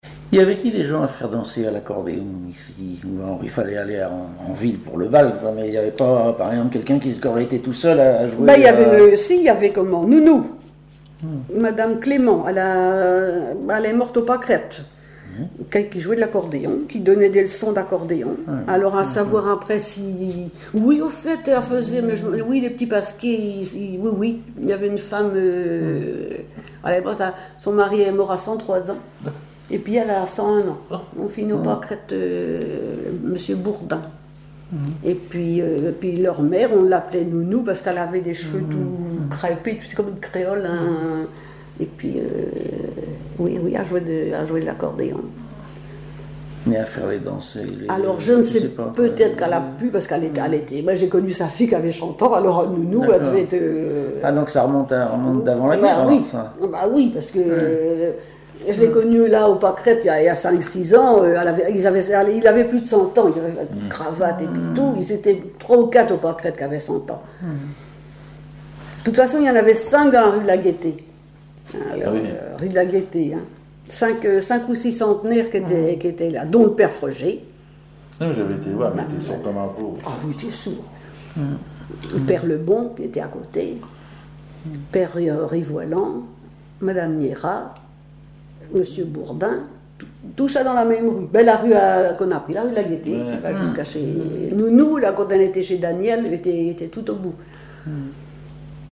Chansons et commentaires
Catégorie Témoignage